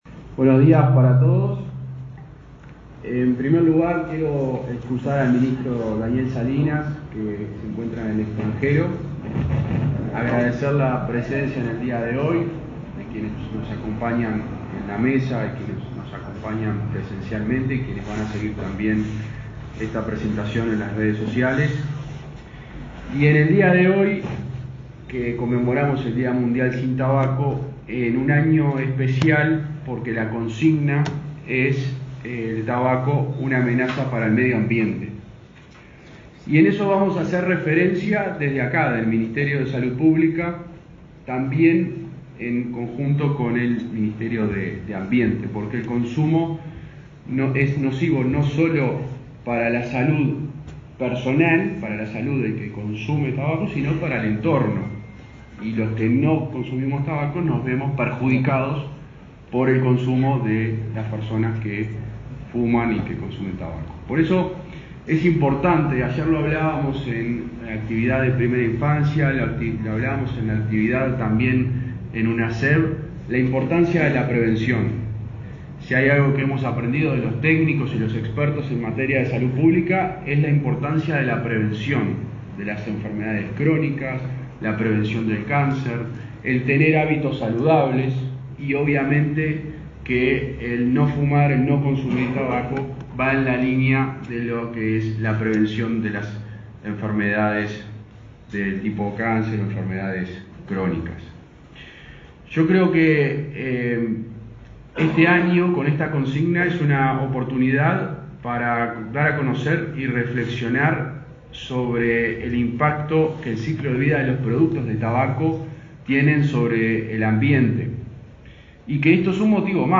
Palabras del ministro interino de Salud Pública, José Luis Satdjian
Este martes 31 en Montevideo, el ministro interino de Salud Pública, José Luis Satdjian, participó en el acto por el Día Mundial Sin Tabaco 2022.